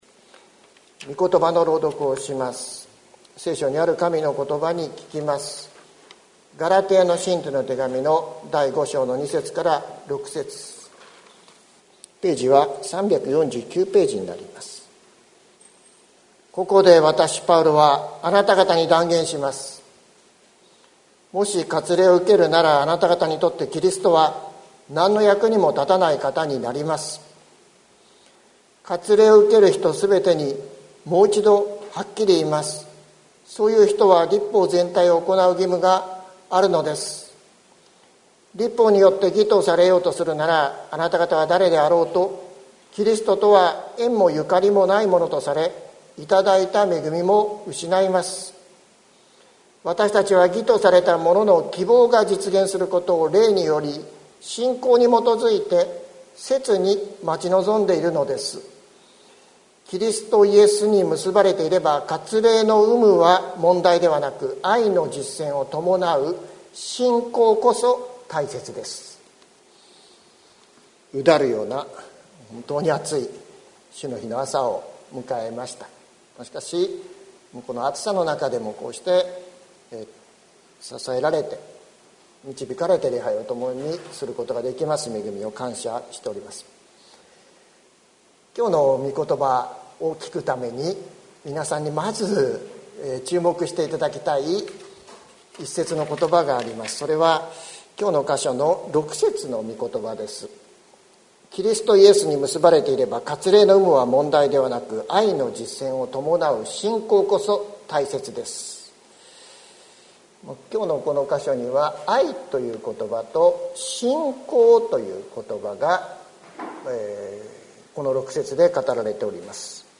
2021年08月08日朝の礼拝「信仰こそ大切です」関キリスト教会
説教アーカイブ。